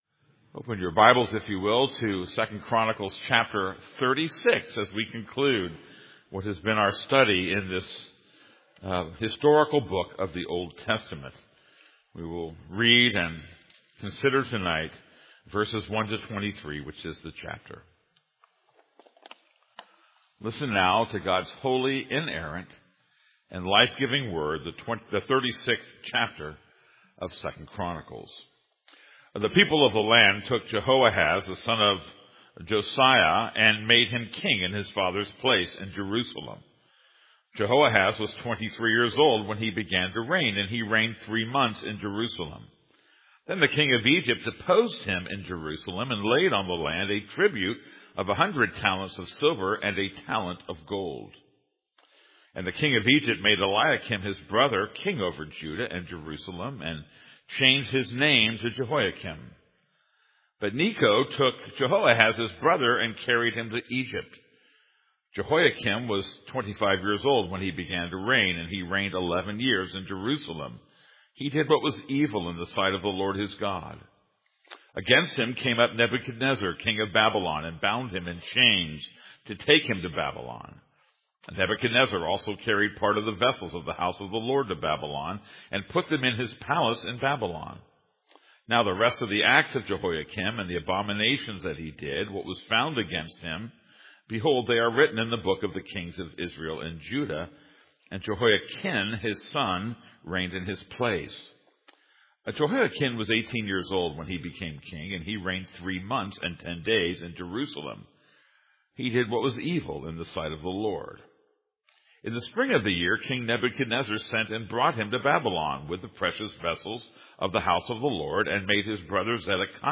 This is a sermon on 2 Chronicles 36:1-23.